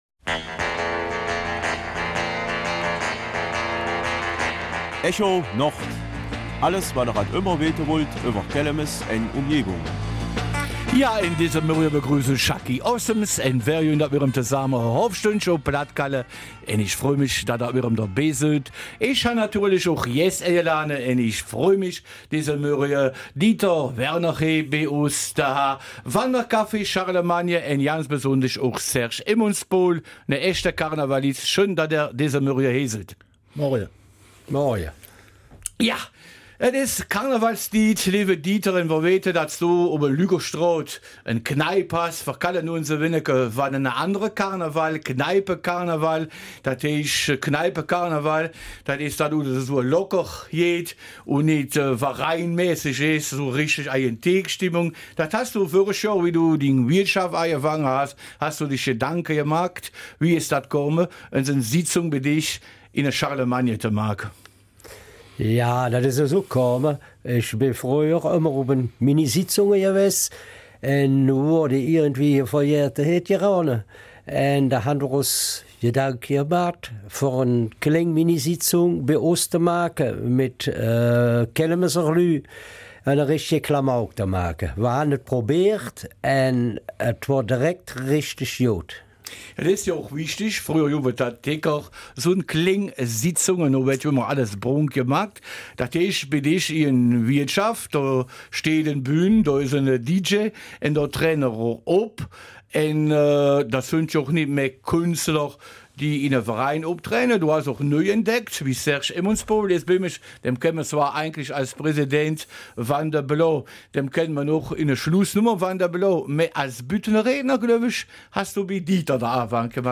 Kelmiser Mundart - 2. Februar